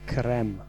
Ääntäminen
Ääntäminen Tuntematon aksentti: IPA: /krɛm/ Haettu sana löytyi näillä lähdekielillä: puola Käännös Konteksti Ääninäyte Substantiivit 1. cream lääketiede US Suku: m .